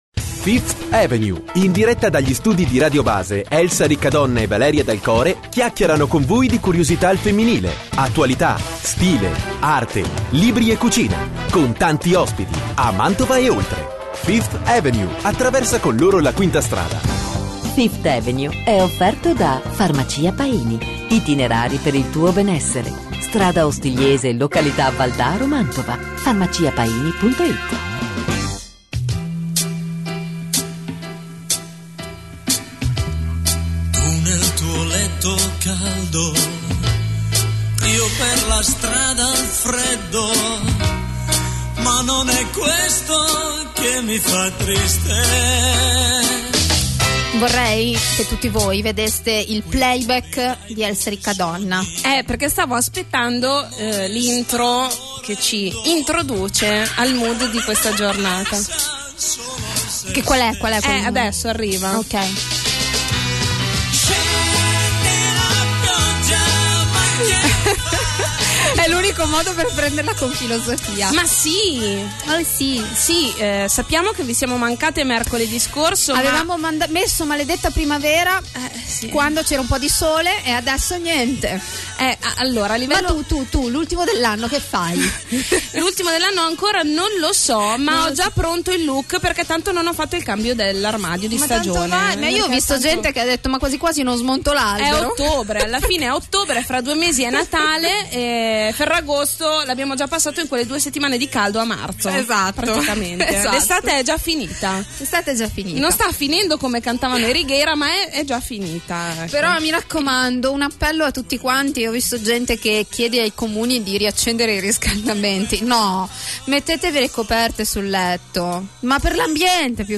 Un ombrello (e un microfono per due)!